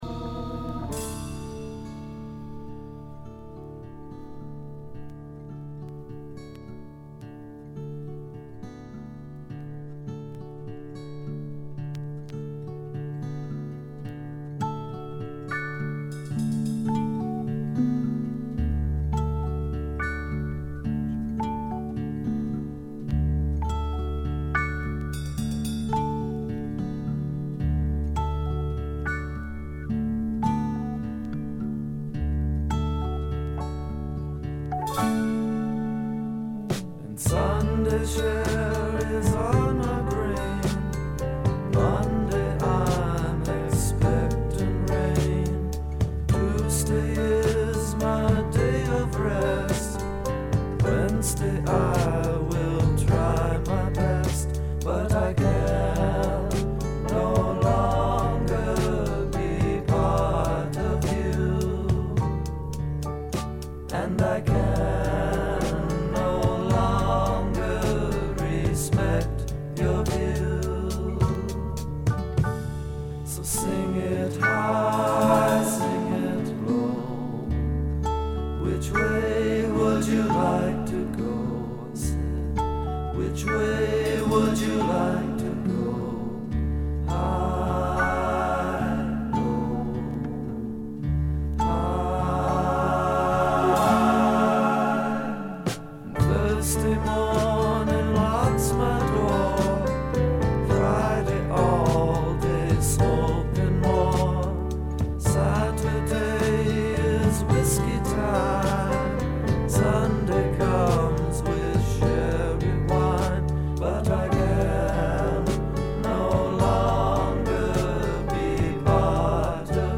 部分試聴ですが静音部での軽微なチリプチ程度。
フォーク、ロック、ポップをプログレ感覚でやっつけたというか、フェイクで固めたような感覚とでもいいましょうか。
試聴曲は現品からの取り込み音源です。